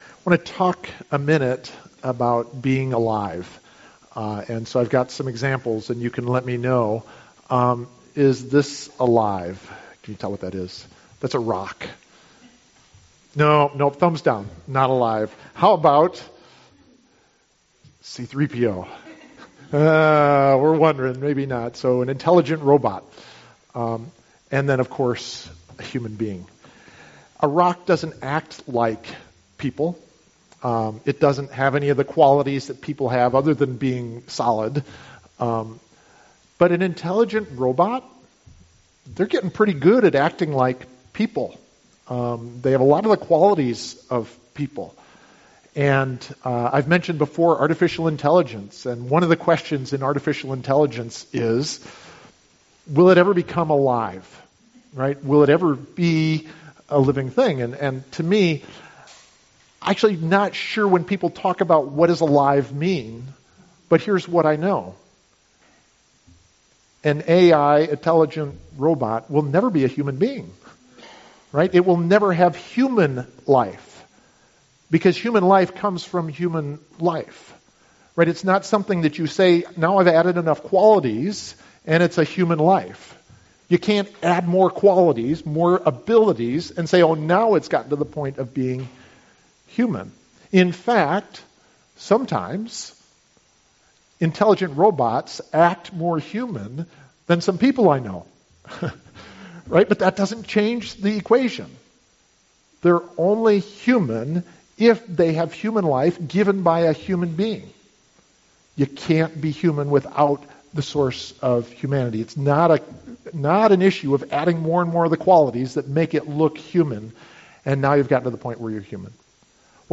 CMC Sermon Handout